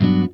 JAZZCHORD1.wav